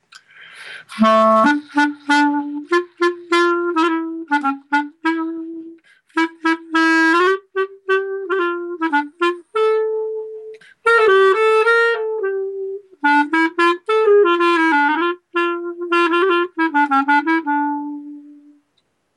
A lot of it is, as I said, it has a certain social function, whether it would be to dance to like a freilach, a happy dance, or a more slower dance, for example, a sher.